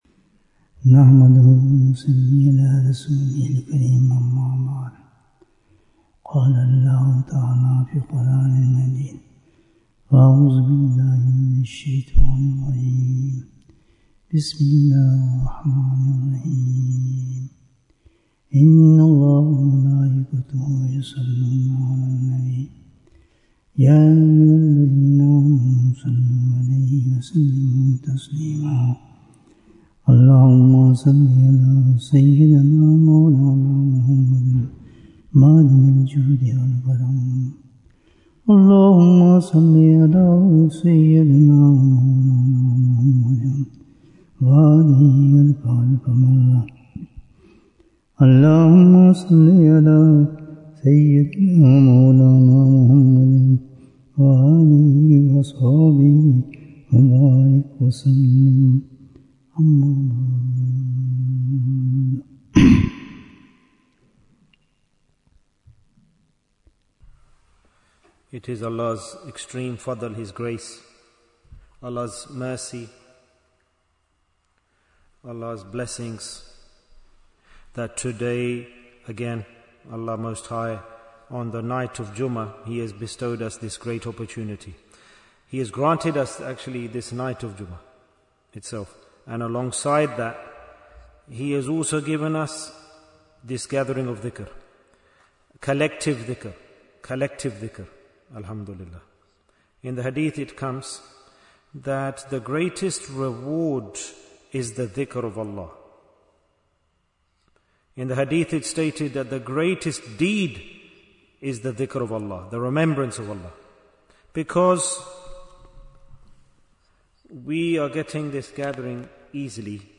The Way to Earn Dunya Bayan, 75 minutes20th November, 2025